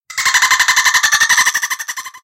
دانلود آهنگ دلفین از افکت صوتی انسان و موجودات زنده
جلوه های صوتی
دانلود صدای دلفین از ساعد نیوز با لینک مستقیم و کیفیت بالا